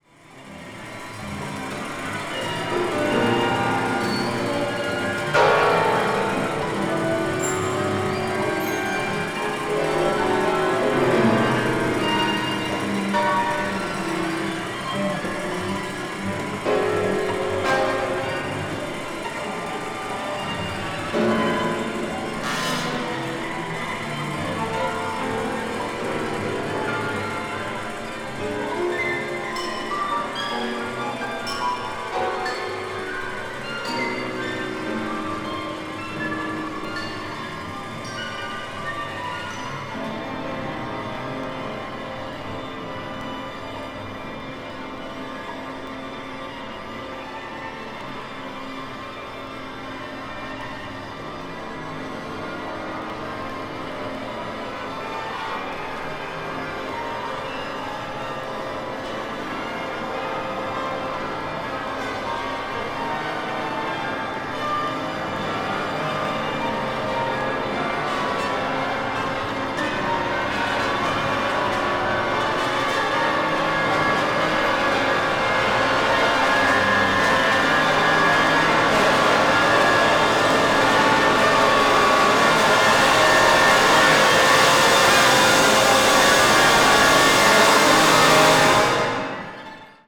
media : EX-/EX-(わずかにチリノイズが入る箇所あり,軽いプチノイズ数回あり)